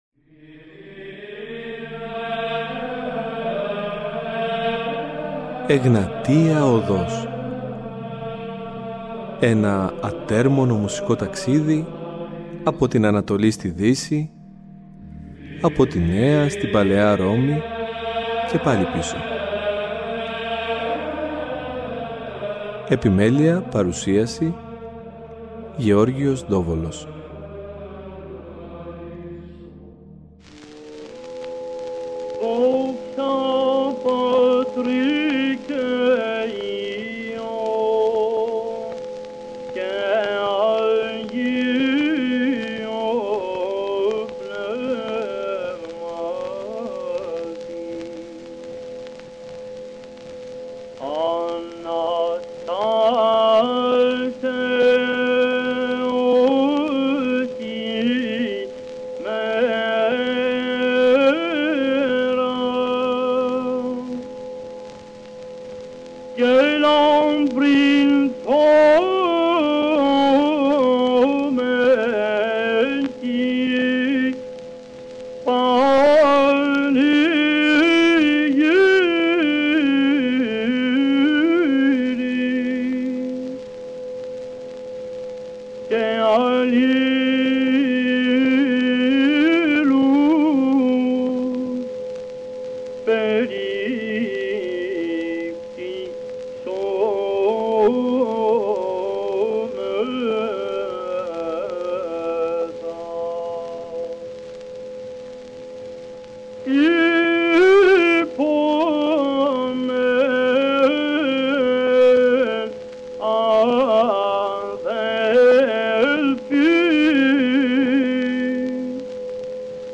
Εδώ κυριαρχεί ο πρώτος ήχος της βυζαντινής μουσικής, ήχος εξαγγελτικός με ιδιαίτερα χαρμόσυνο και διασταλτικό χαρακτήρα.
Βυζαντινη Μουσικη